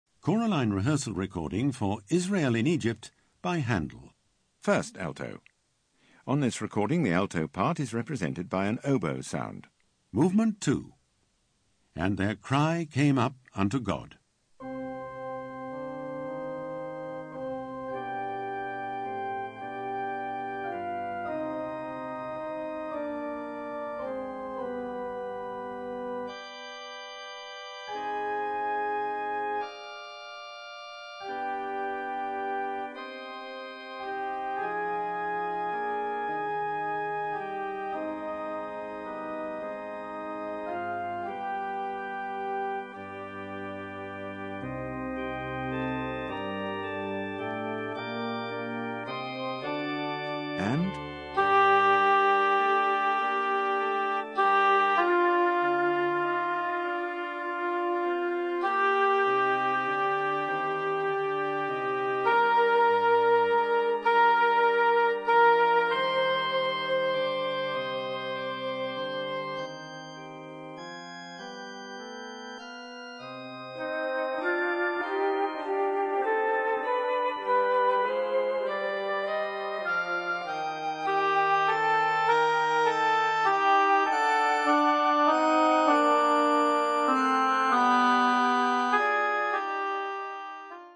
Alto
High Quality made by BBC Sound Engineer
Easy To Use narrator calls out when to sing
Don't Get Lost narrator calls out bar numbers
Be Pitch Perfect hear the notes for your part
Vocal Entry pitch cue for when you come in